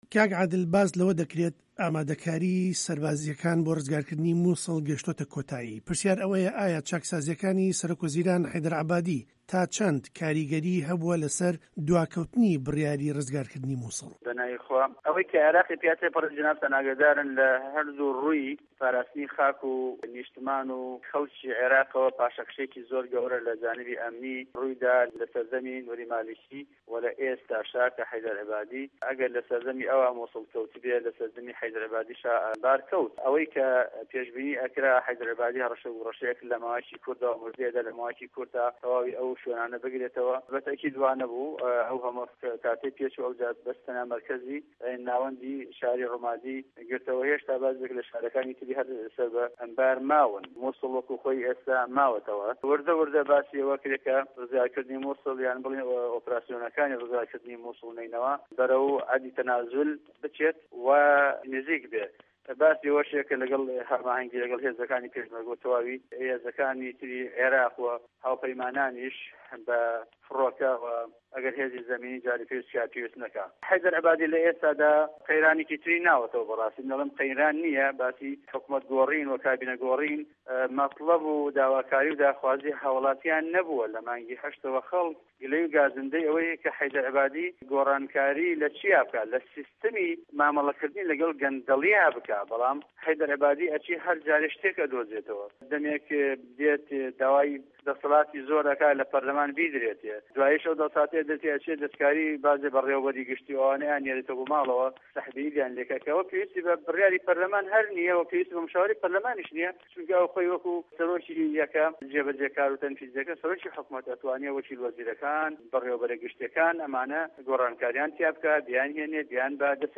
گفتووگۆ له‌گه‌ڵ عادل نوری